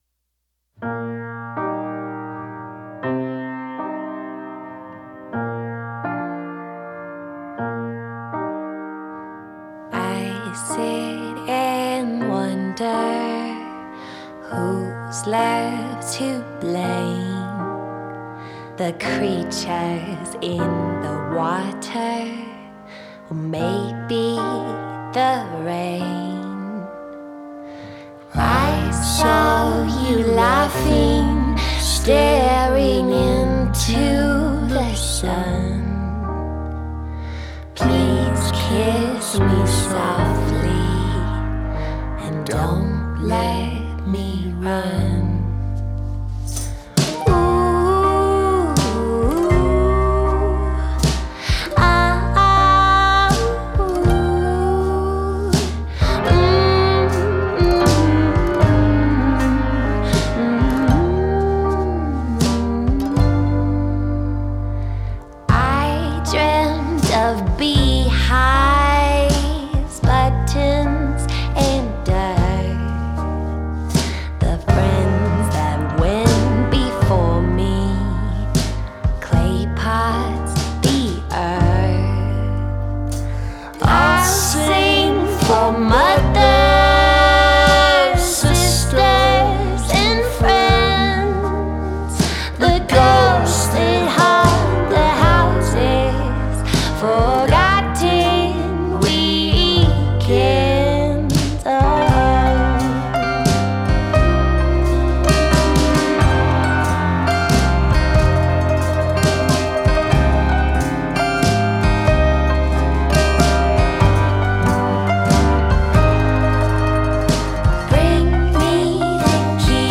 Genre: Indie Rock / Folk